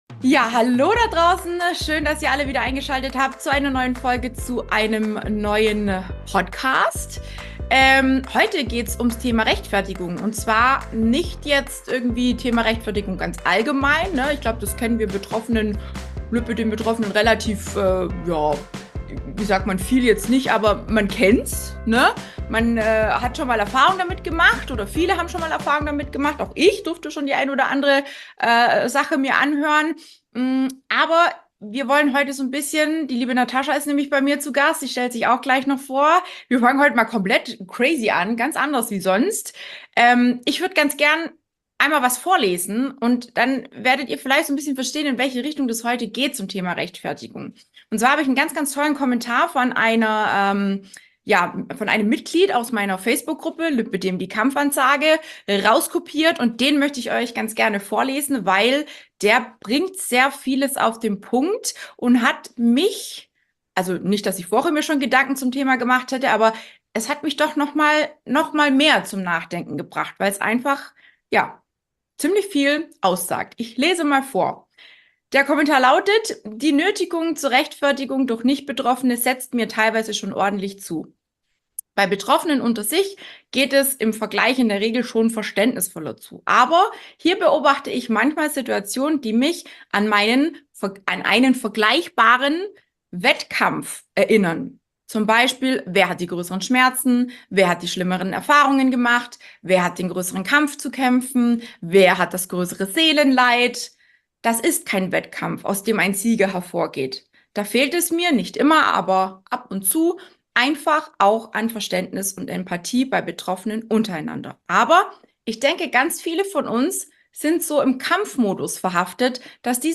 In unserem Gespräch sprechen wir darüber, welche körperlichen und auch psychischen Auswirkungen diese Vorurteile haben können, vor allen Dingen, wenn man sich dann auch noch innerhalb Gleichgesinnter rechtfertigen muss. Wir diskutieren die häufigsten Mythen und Missverständnisse, die sich ums Lipödem drehen, sowie die unangenehmen Anschuldigungen, denen sich viele Betroffene ausgesetzt sehen. Es geht auch darum, wie Neid und Unsicherheit innerhalb der Gemeinschaft das Wohlbefinden der Betroffenen beeinträchtigen können.